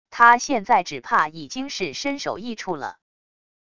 他现在只怕已经是身首异处了wav音频生成系统WAV Audio Player